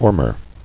[or·mer]